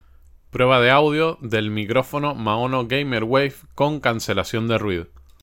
Pruebas de Audio del Maono GamerWave
Además, el micrófono cuenta con tecnología de Reducción de Ruido con un solo toque, asegurando una reproducción de sonido clara y precisa.
Maono-GamerWave-con-cancelacion.mp3